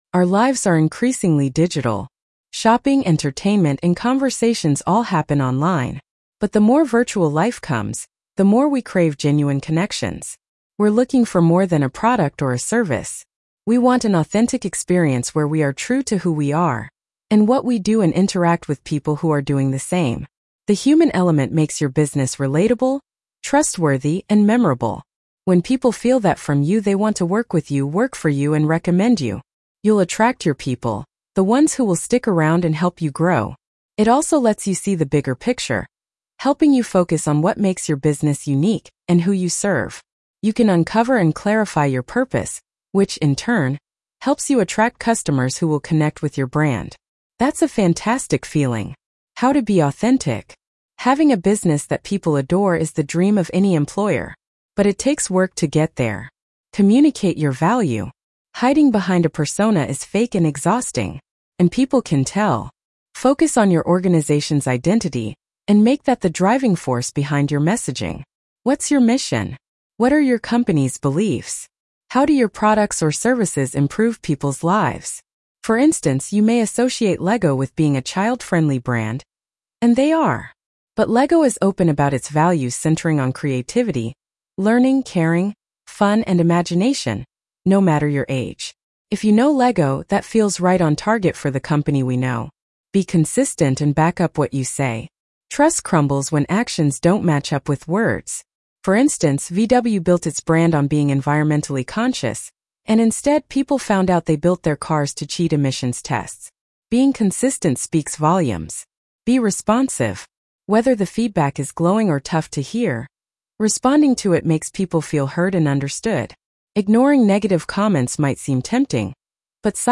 Be the Real McCoy Blog Narration.mp3